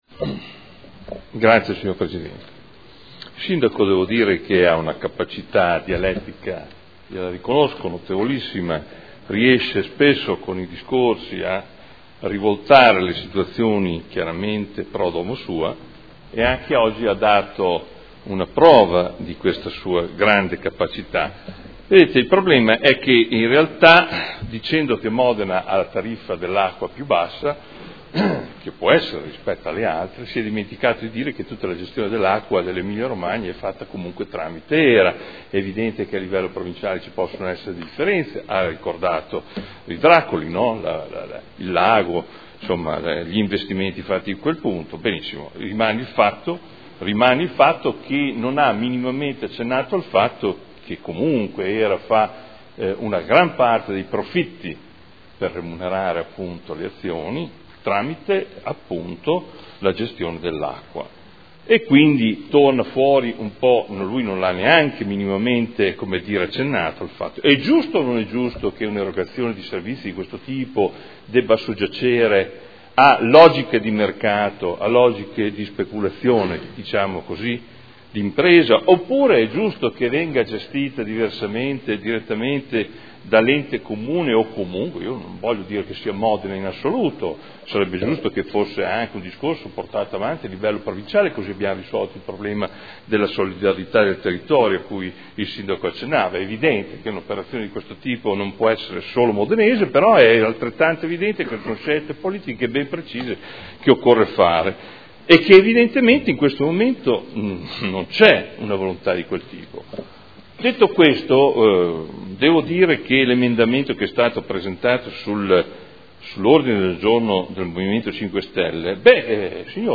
Adolfo Morandi — Sito Audio Consiglio Comunale
Dichiarazione di voto su proposta di deliberazione "Scioglimento di HSST – MO S.p.A. e determinazioni in ordine alla partecipazione in Hera S.p.A.", Ordine del Giorno emendato firmato dai consiglieri Bussetti, Bortolotti e Rabboni (Movimento5Stelle) avente per oggetto "Impegno strategico verso la pubblicizzazione del servizio idrico" e Ordine del Giorno firmato dai consiglieri Arletti e Trande (PD) avente per oggetto "Mantenere la governance pubblica delle multi-utility della Provincia di Modena e gli impegni sugli investimenti pubblici utili alla città"